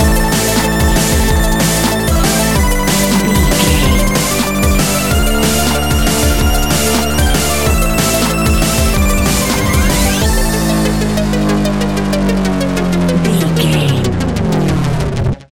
Fast paced
Aeolian/Minor
hard
intense
high tech
futuristic
energetic
driving
repetitive
dark
aggressive
drum machine
break beat
electronic
sub bass
instrumentals
synth leads
synth bass